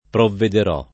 provvedere [provved%re] v.; provvedo [provv%do] — fut. provvederò [